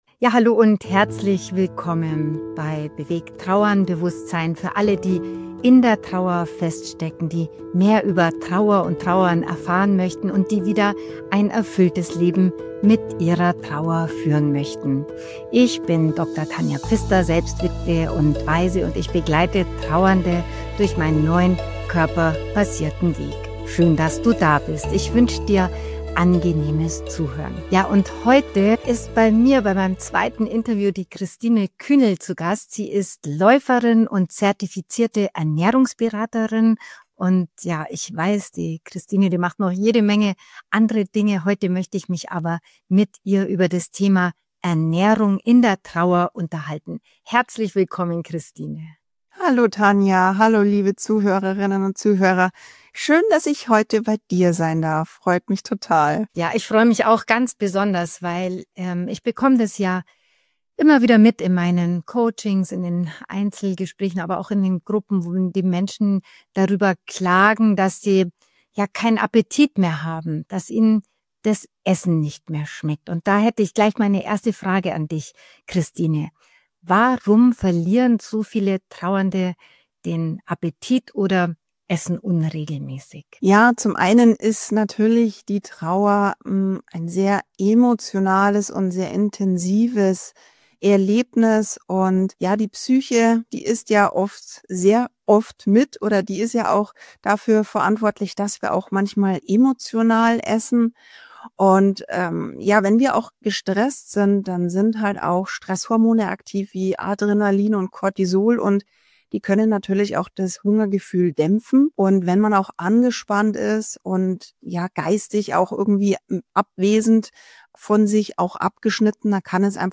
#28 - Ernährung in der Trauer - Im Interview